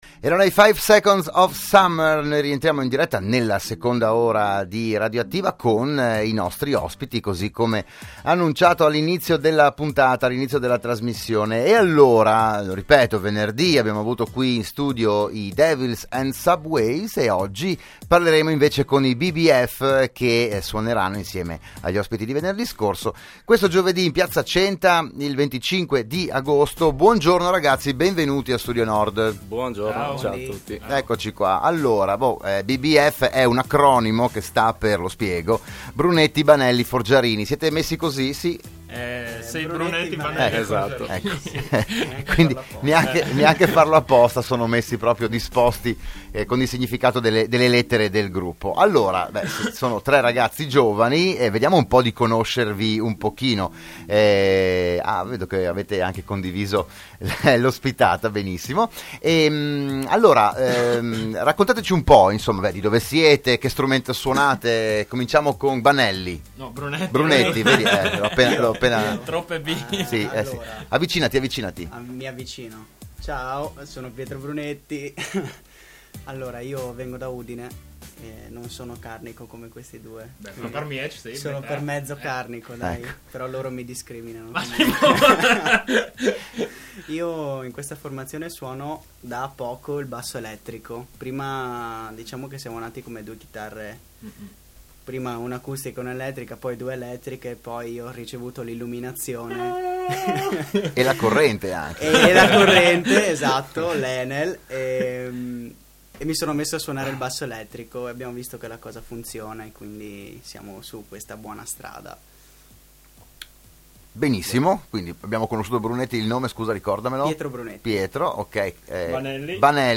Giovedì 25 agosto 2016, dalle ore 21.00 i tre cantautori e musicisti si esibiranno dal palco di piazza Centa. Ascolta la loro intervista a RadioAttiva
Proponiamo il podcast dell’intervista ai tre ospiti di “RadioAttiva“, la trasmissione di Radio Studio Nord